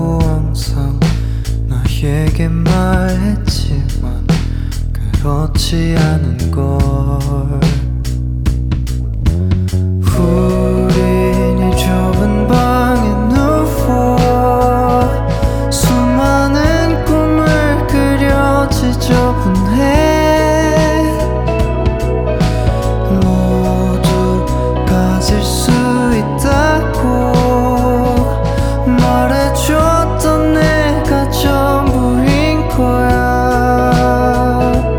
Жанр: Поп музыка / Рок
Rock, Pop, K-Pop